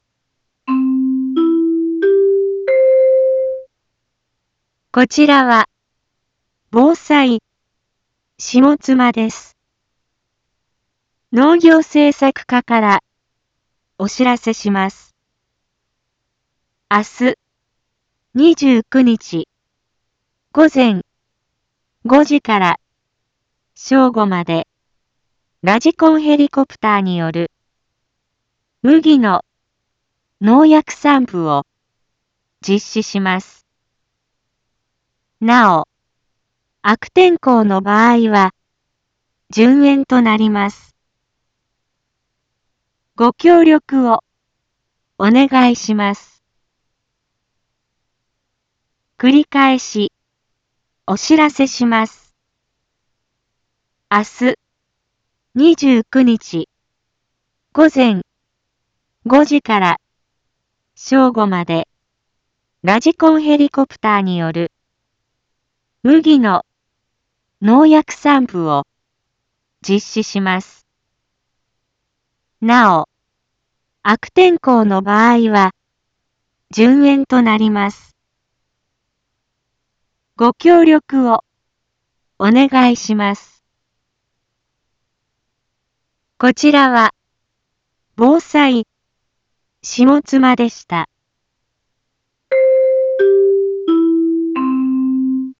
一般放送情報
Back Home 一般放送情報 音声放送 再生 一般放送情報 登録日時：2025-04-28 12:31:52 タイトル：麦のラジコンヘリによる防除について インフォメーション：こちらは、ぼうさいしもつまです。